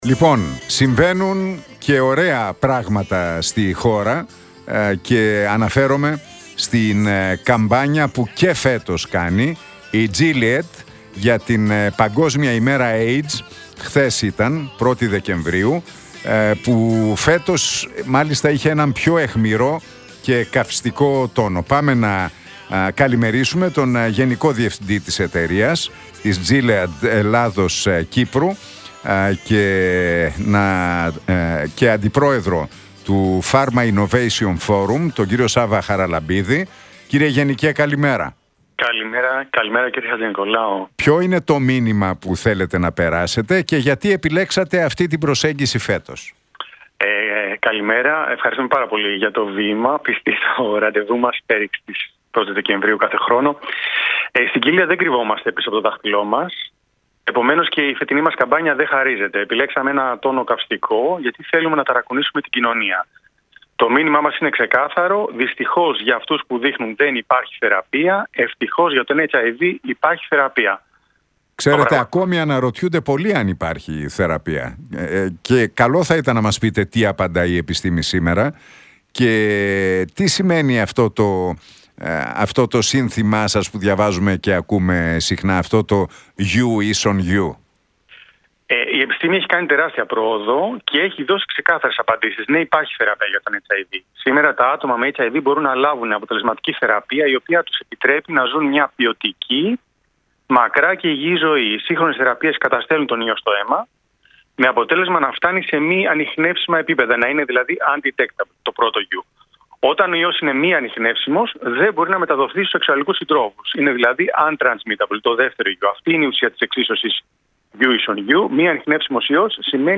στον Realfm 97,8 για τις δράσεις με αφορμή την Παγκόσμια Ημέρα κατά του AIDS